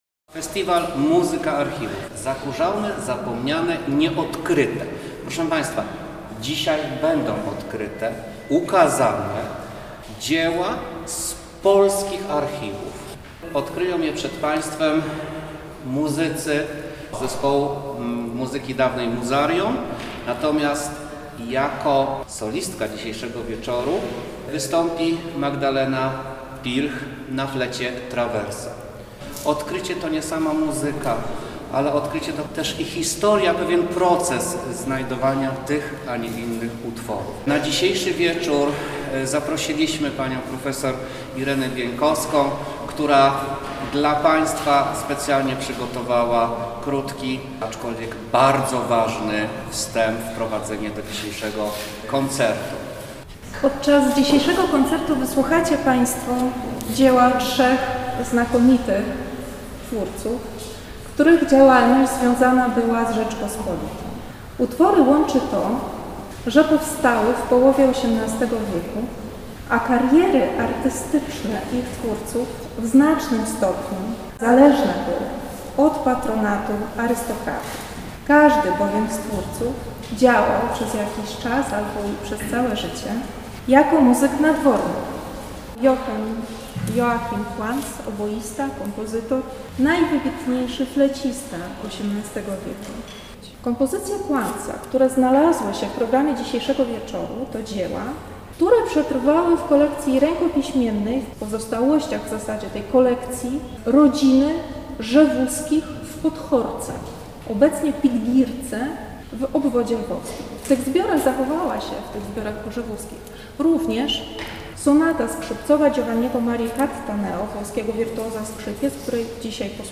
Zapowiedź koncertu